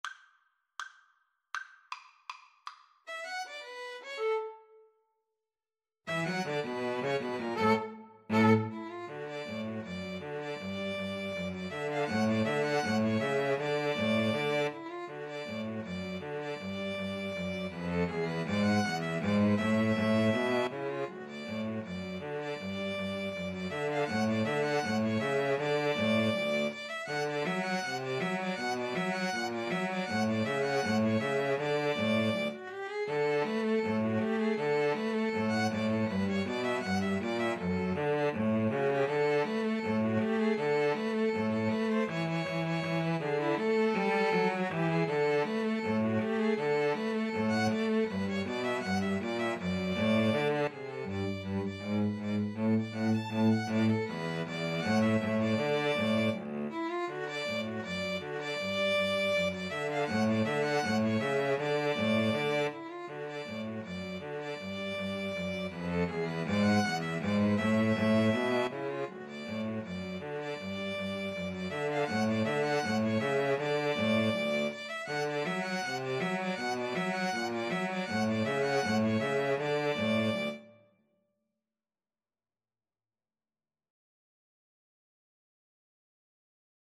Free Sheet music for String trio
D major (Sounding Pitch) (View more D major Music for String trio )
=250 Presto (View more music marked Presto)
Jazz (View more Jazz String trio Music)